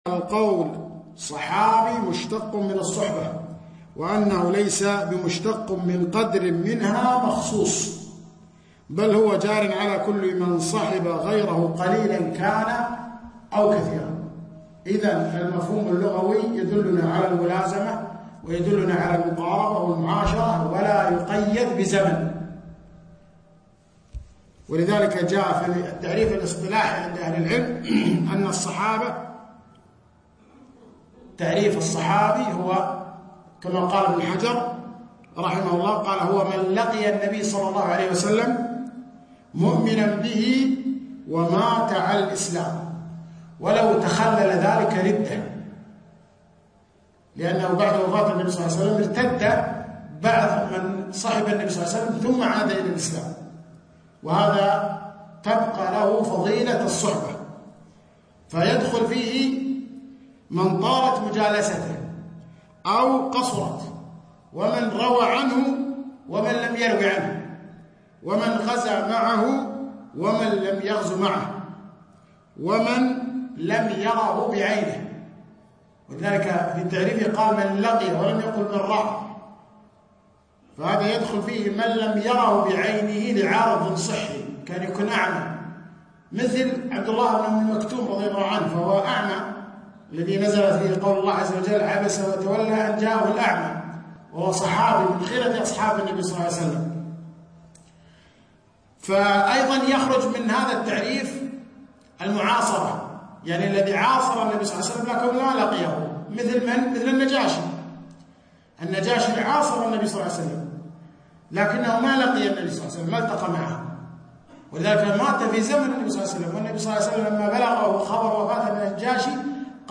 محاضرة - عقيدتنا في الصحابة